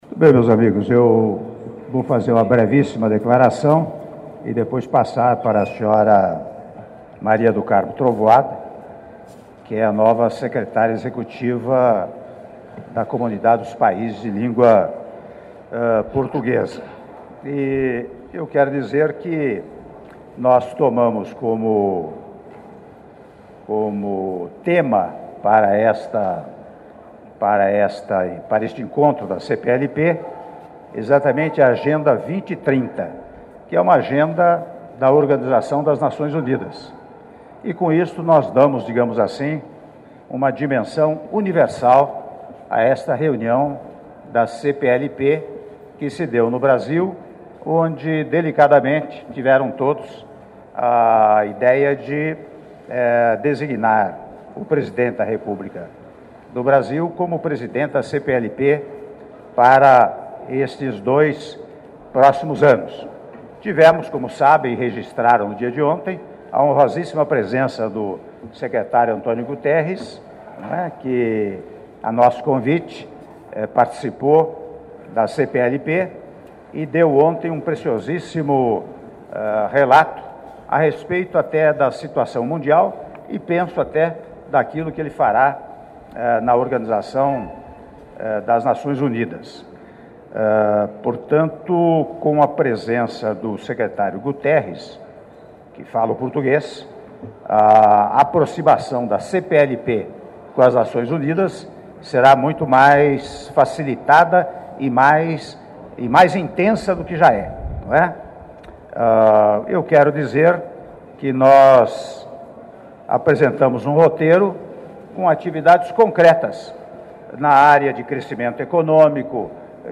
Áudio da declaração à imprensa do presidente da República, Michel Temer, após sessão solene de encerramento da XI Conferência de Chefes de Estado e de Governo da Comunidade dos Países de Língua Portuguesa (04min28s)